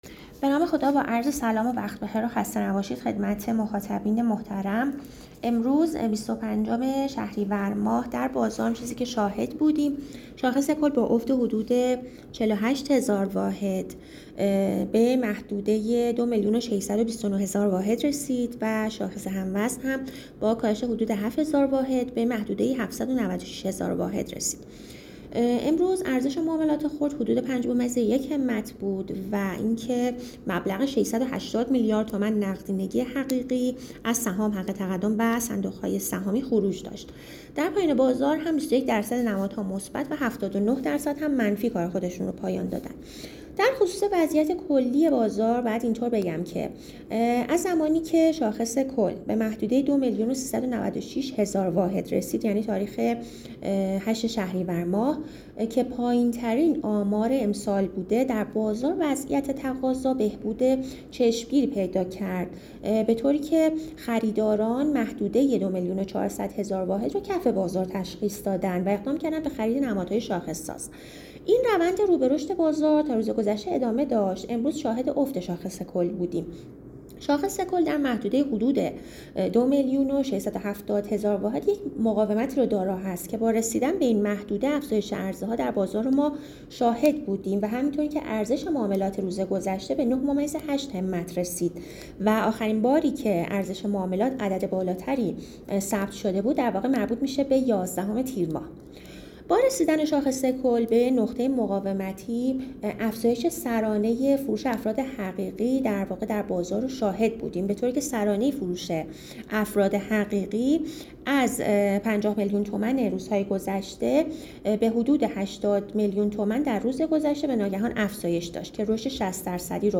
کارشناس بازار سرمایه